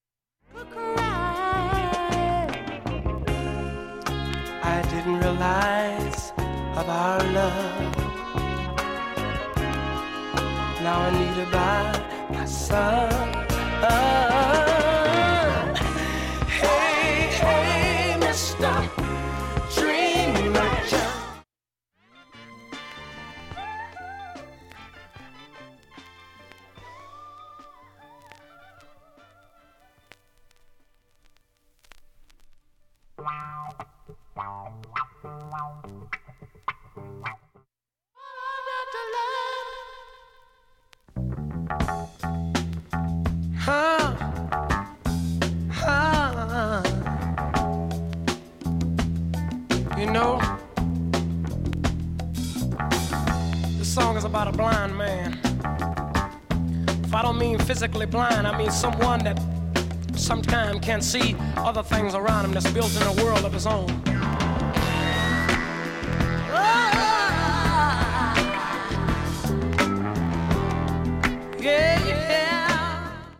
３回までのかすかなプツが５箇所 単発のかすかなプツが１箇所